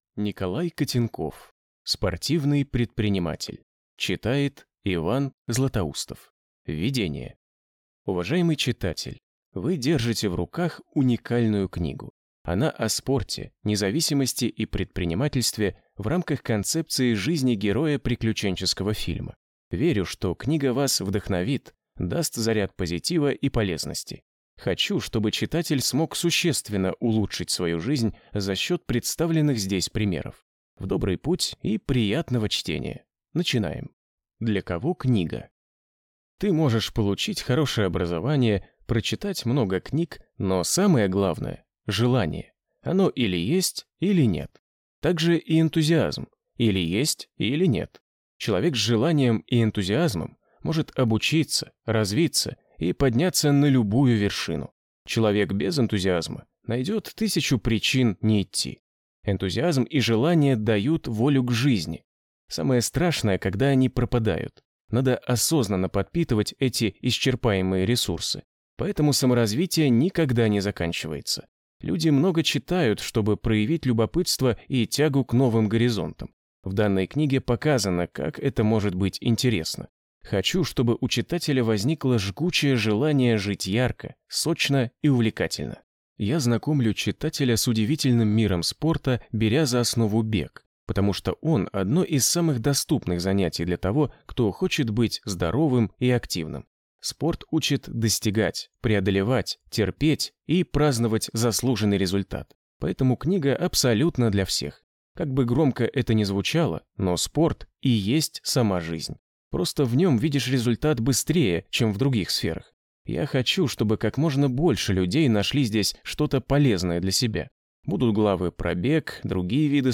Аудиокнига Спортивный предприниматель | Библиотека аудиокниг